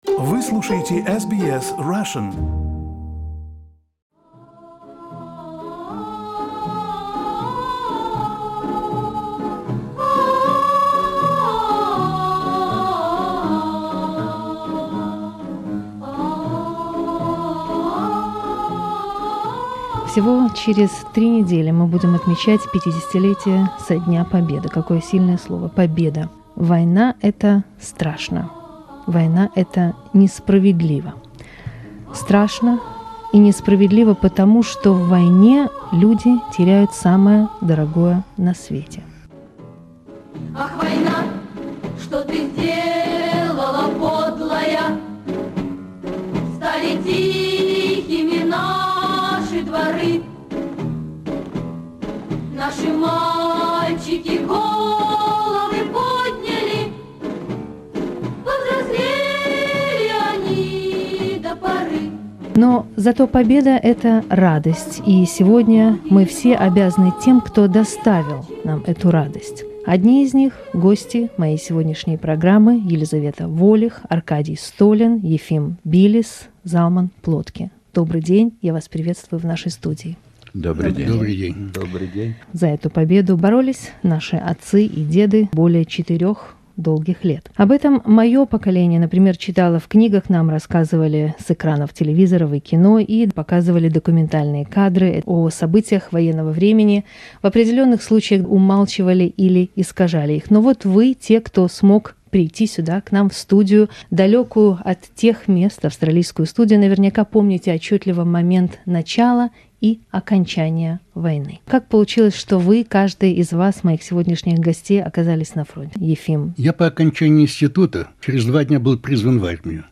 Архивное интервью SBS Russian с ветеранами Великой Отечественной войны, которое было записано в мае 1995 года.